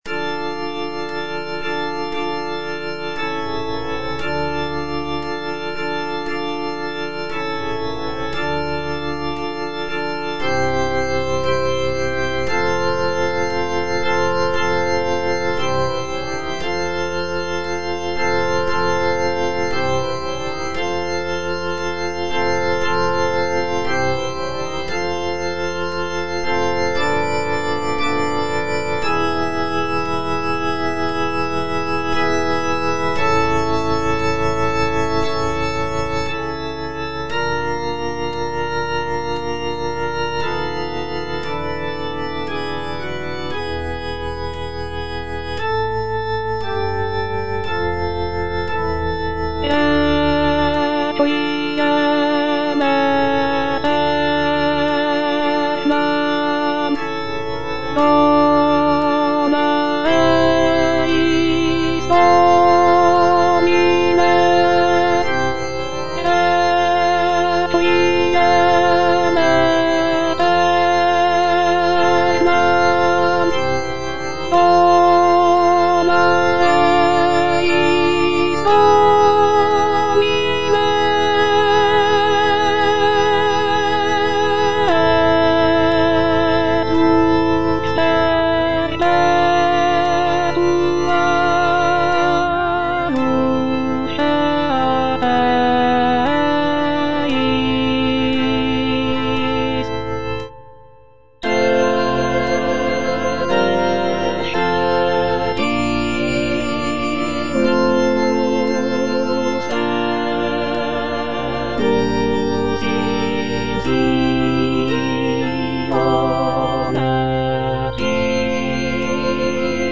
F. VON SUPPÈ - MISSA PRO DEFUNCTIS/REQUIEM Introitus (alto II) (Voice with metronome) Ads stop: auto-stop